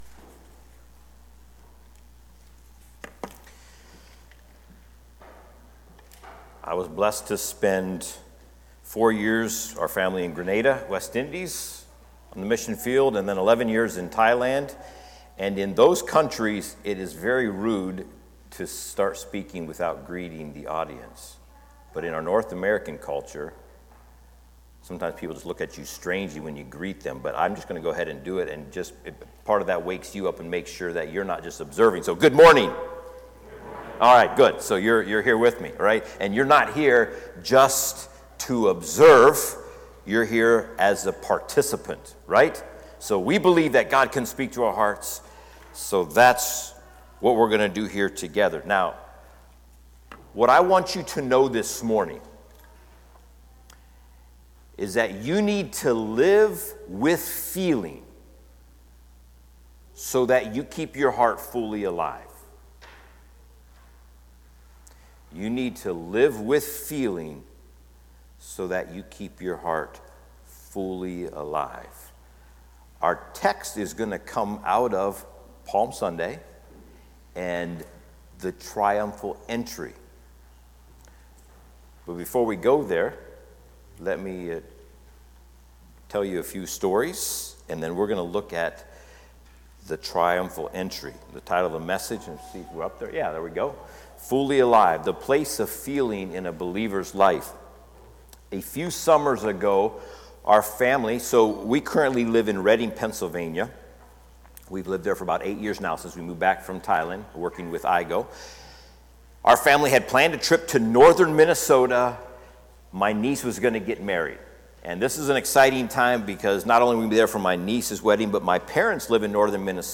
Series: Guest Speakers, Sunday Sermons